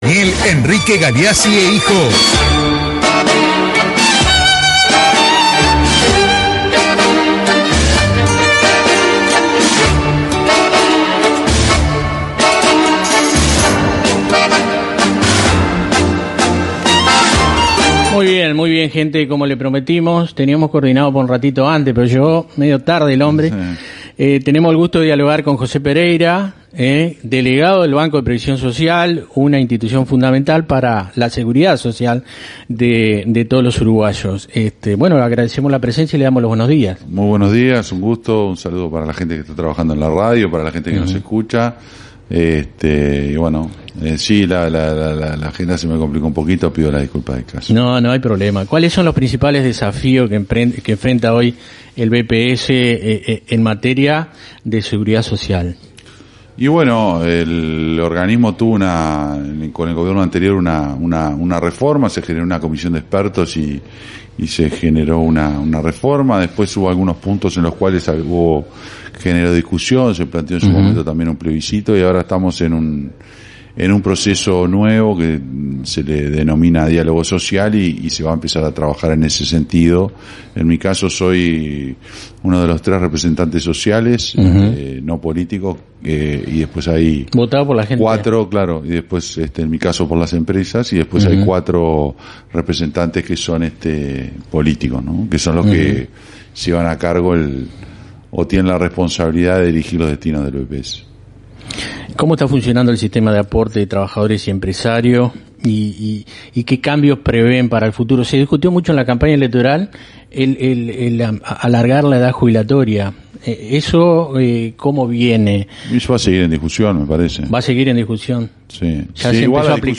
En una entrevista en Cero Estres programa radial emitido por radio Turística AM 1410, José Pereira, representante de las empresas en el directorio del Banco de Previsión Social (BPS), habló sin rodeos sobre los principales desafíos de la seguridad social en Uruguay. Recordó que, tras la última reforma impulsada por el gobierno anterior, quedaron puntos abiertos a discusión, como la edad de jubilación.